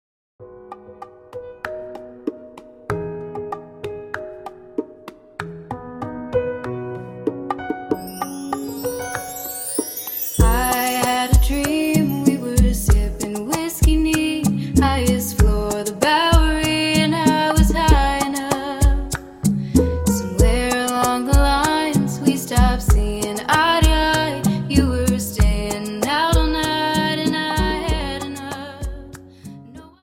Dance: Rumba 25